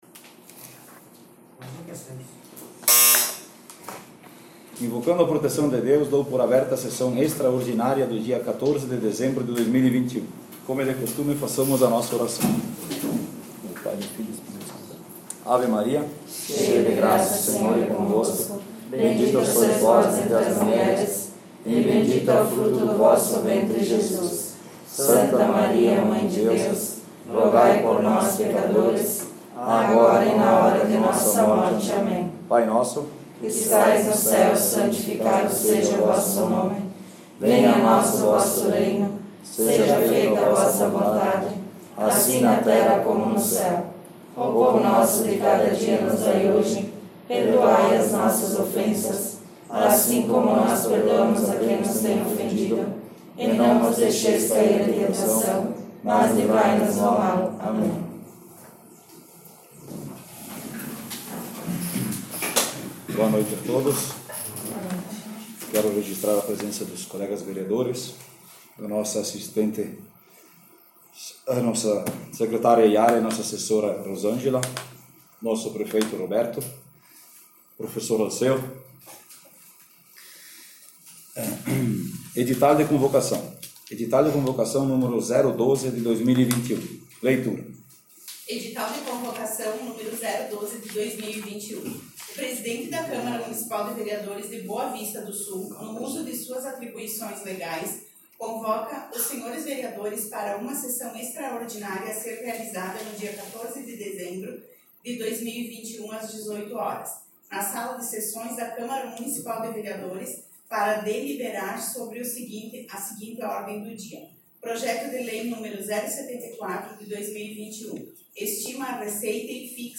Sessão Extraordinária - 14 de dez 17.57.mp3